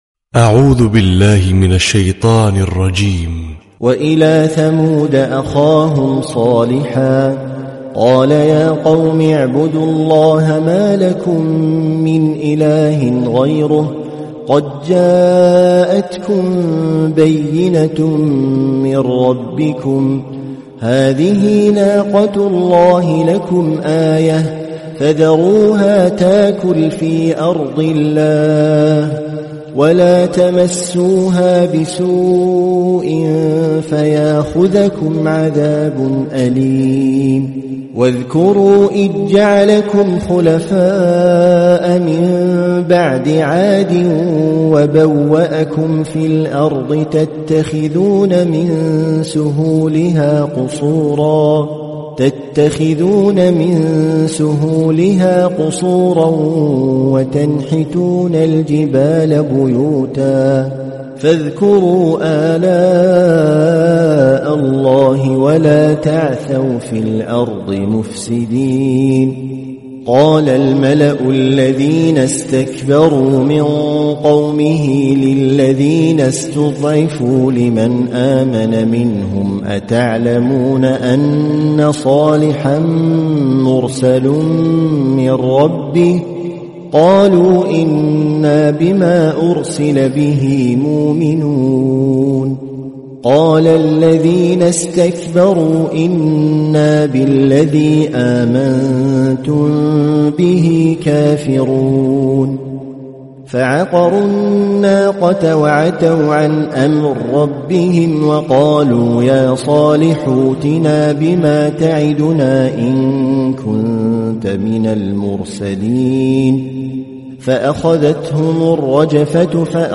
🕋🌻•تلاوة صباحية•🌻🕋
★برواية السوسي عن أبي عمرو★